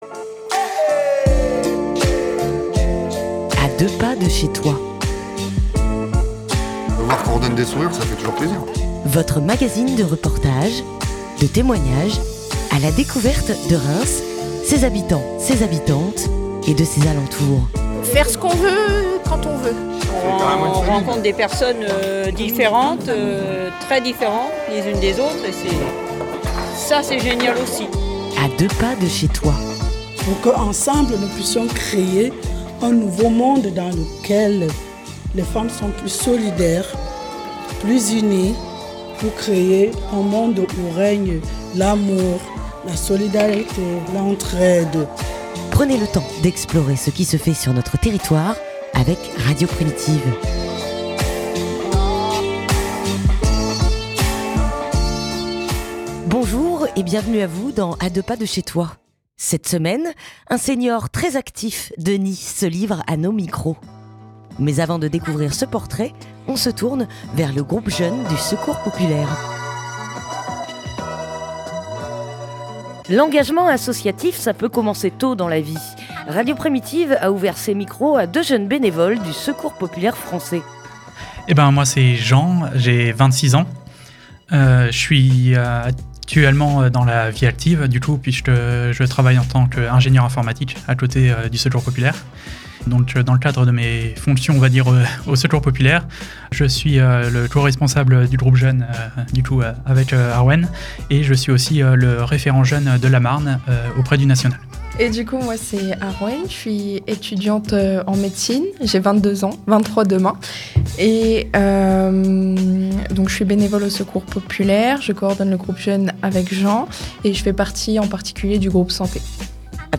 -Radio Primitive a ouvert ses micros à deux bénévoles du secours populaire français. Iels gèrent le groupe jeune du secours populaire et nous parlent de leurs envies et leurs missions.